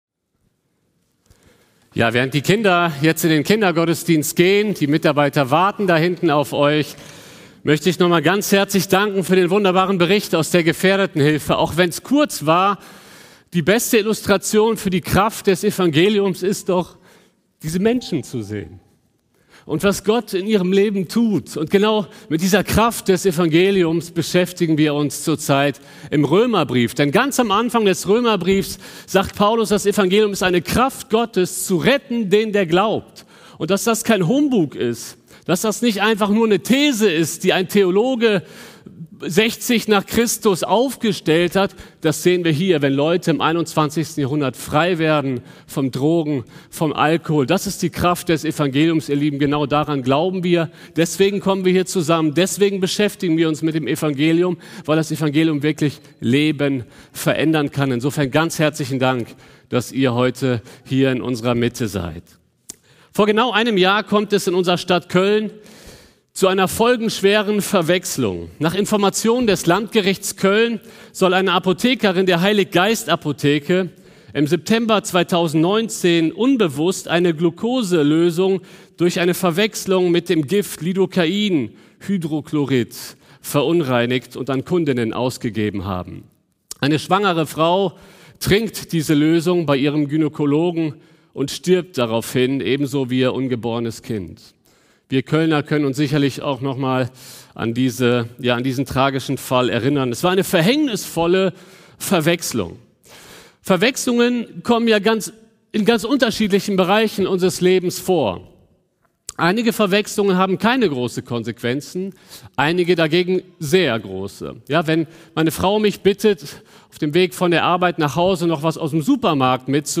September 2020 Predigt-Reihe